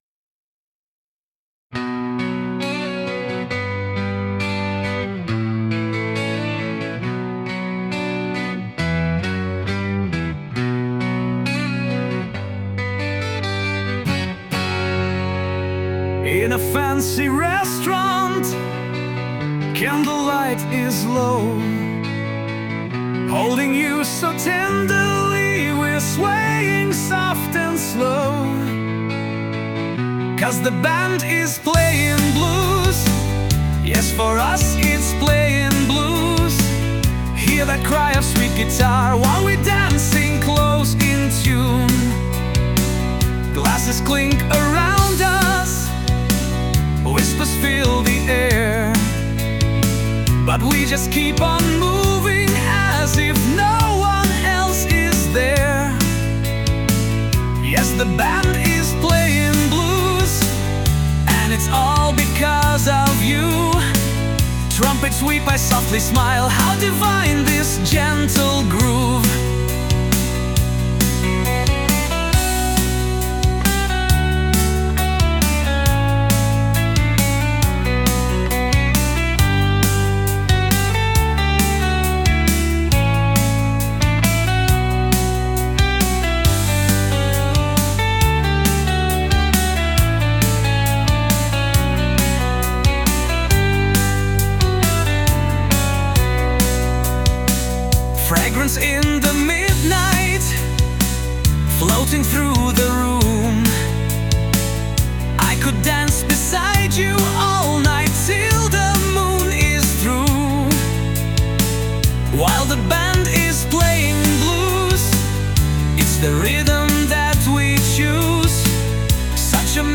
English, Romantic, Blues, Rock | 15.04.2025 17:46